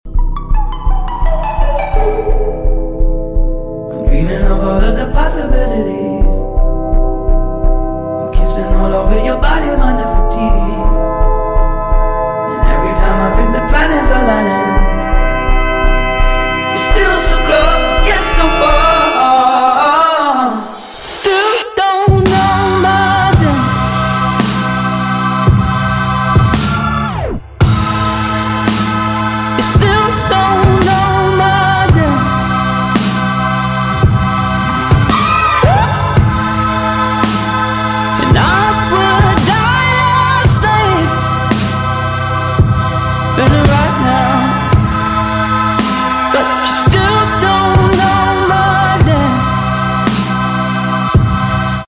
asmr cozy night routine ⭐🎀☕🧸 sound effects free download